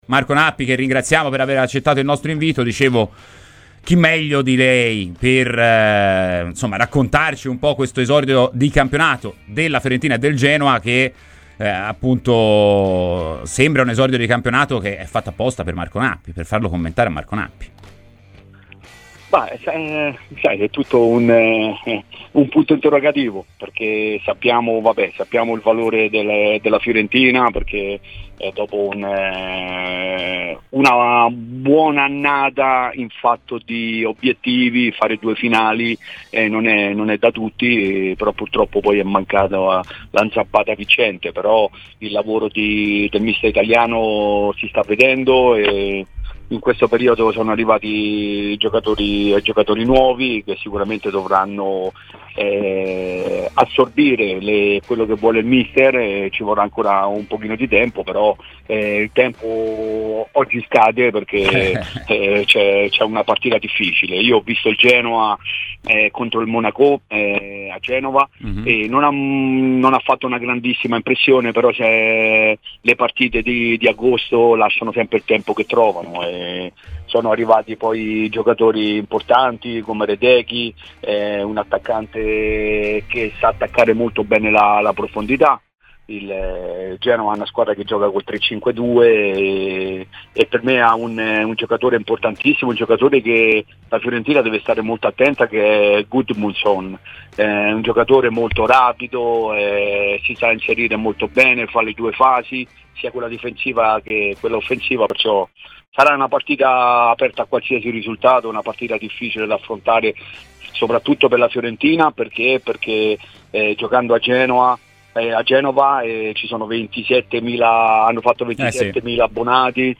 Marco Nappi, soprannominato "Nippo", ex Fiorentina e Genoa, è intervenuto ai microfoni di Radio Firenze Viola, durante la trasmissione "Firenze in campo".